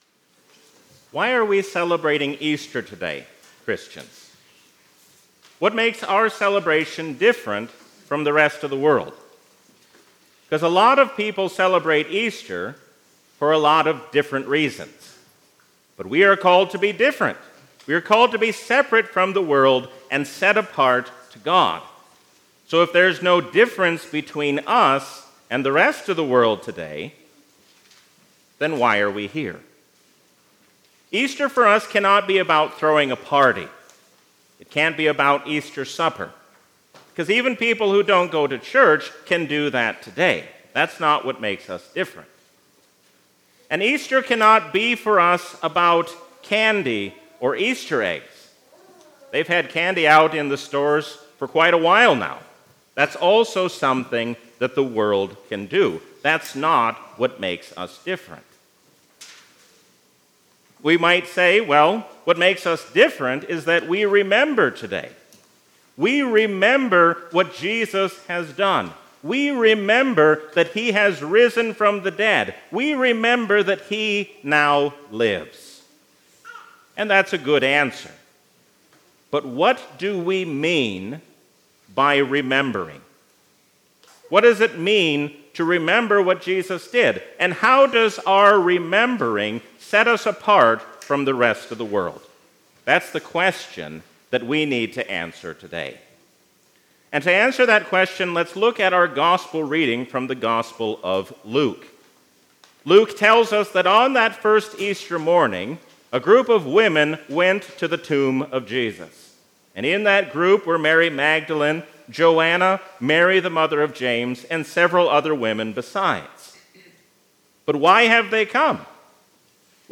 A sermon from the season "Easter 2023."